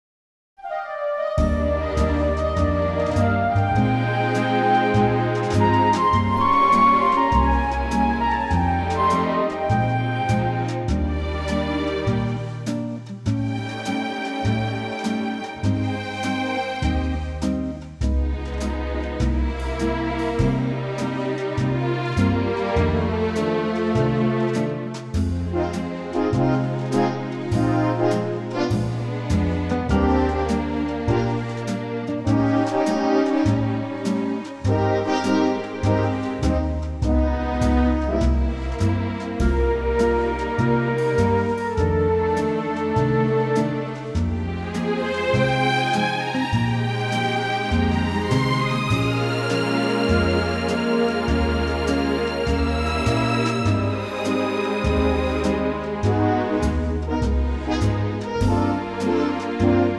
Unique Backing Tracks
key - Eb - vocal range - D to Eb